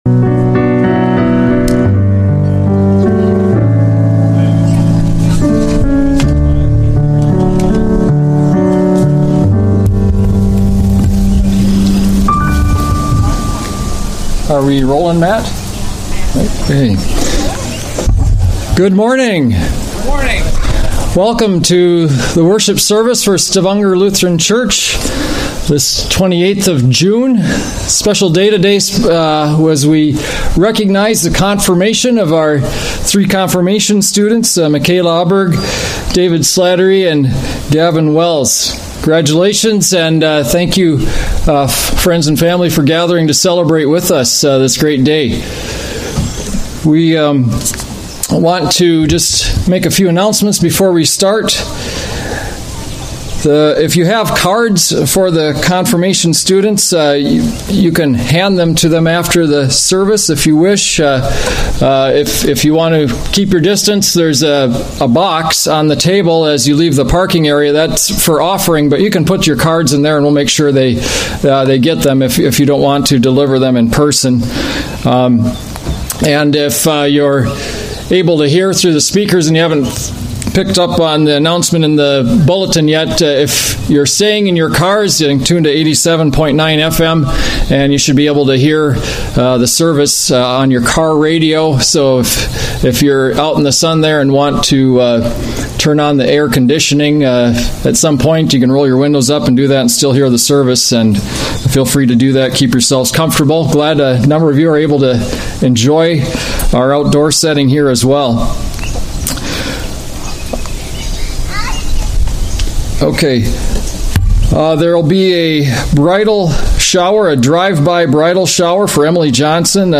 Sunday Worship / Confirmation - Part 1